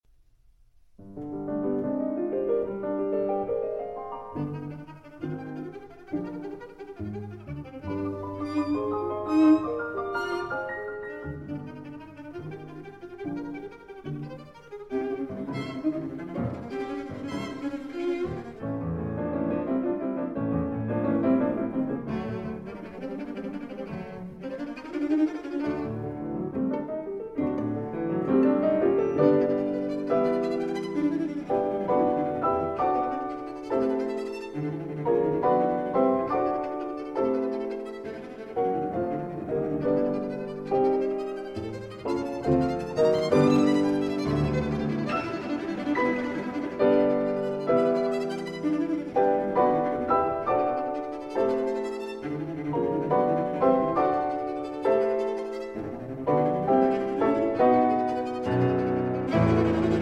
cello
violin
piano